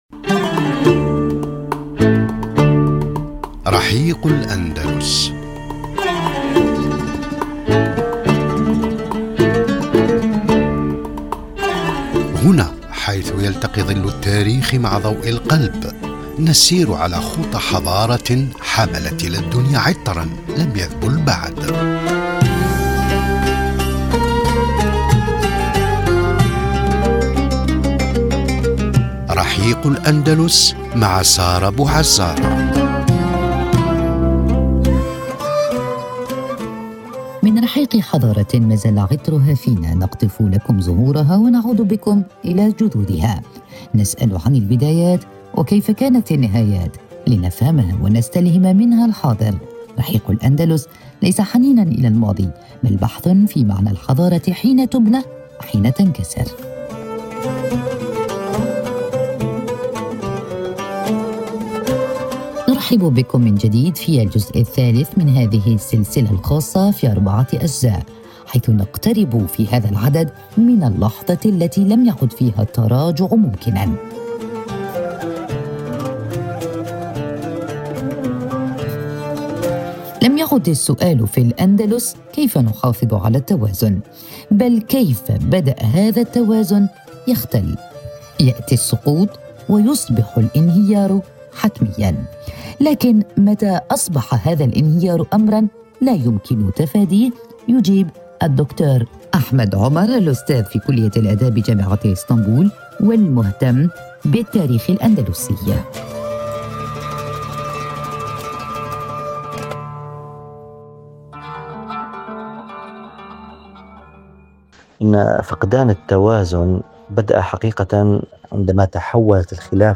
قراءة تحليلية